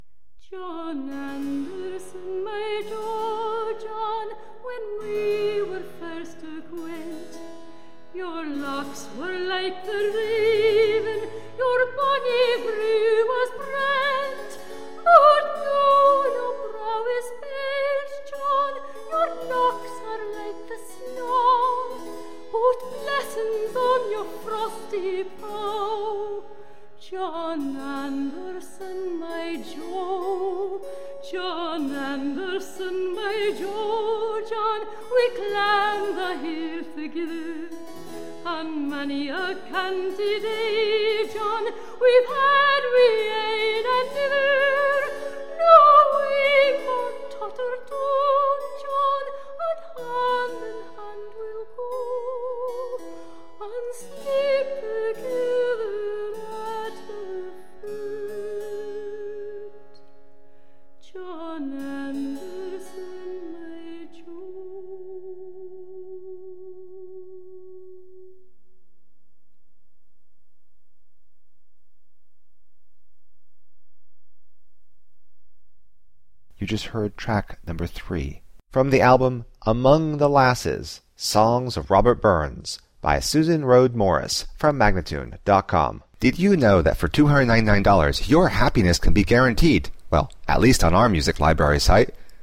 Tagged as: Classical, Folk, Celtic